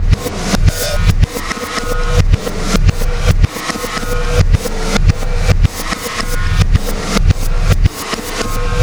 Black Hole Beat 09.wav